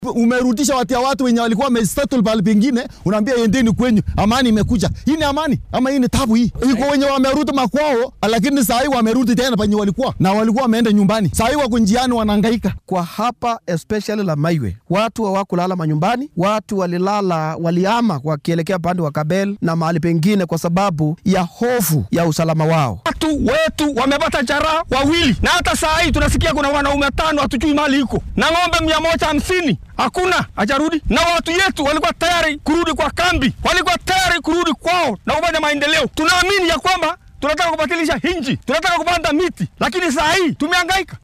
Qaar ka mid ah dadweynaha ku nool tuulada Lamaywe ayaa dareenkooda la wadaagay warbaahinta.